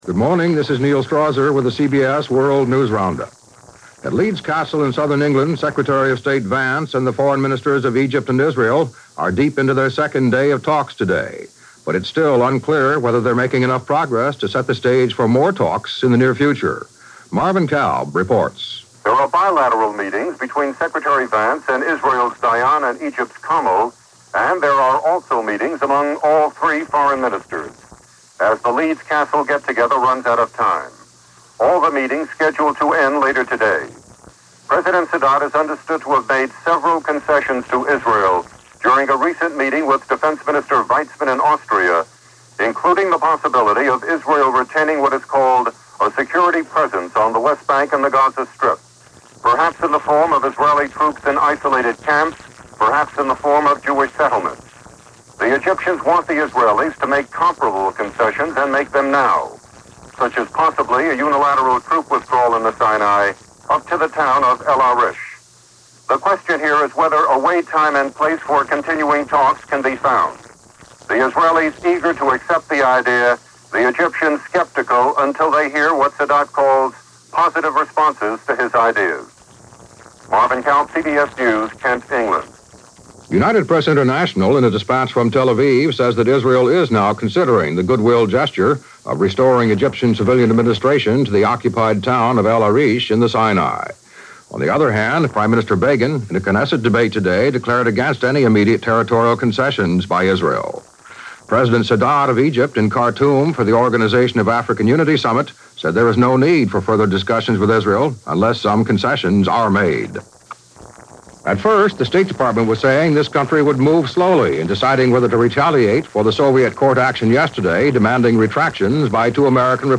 And while negotiations toward a Middle-East Peace Settlement continued, that’s a little of what happened, this July 19, 1978 as reported by The CBS World News Roundup.